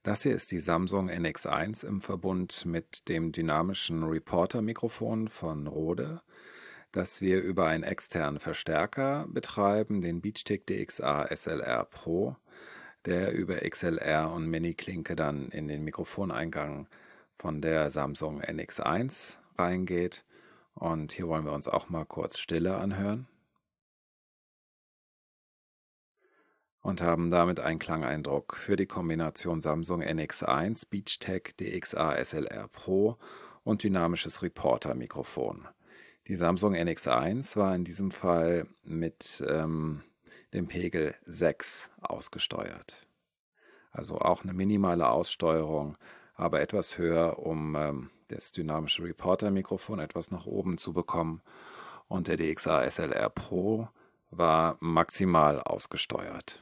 Hier inklusive Denoiser:
Samsung NX1 mit Beachtek DXA-SLR PRO und Rode Reporter (Dynamisches Handmikro)
SamsungNX1_DynaMikRode_norm_Denoise.wav